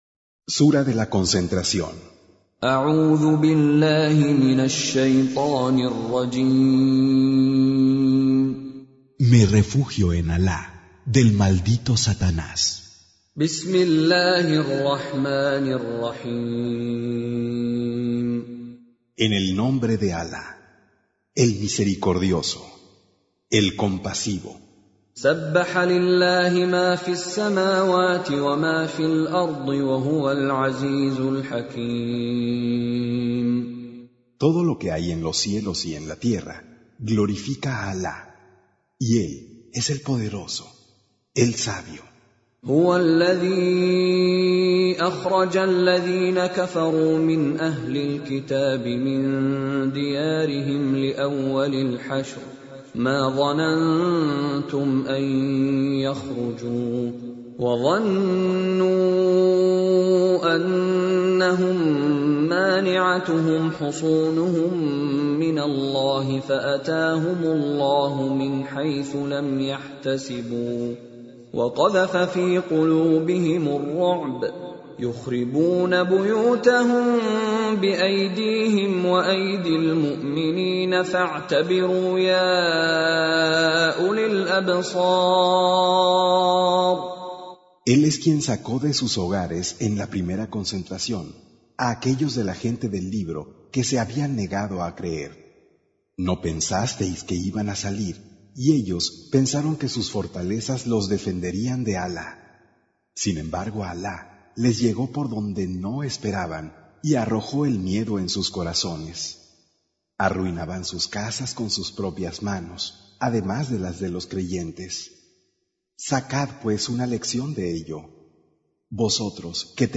Traducción al español del Sagrado Corán - Con Reciter Mishary Alafasi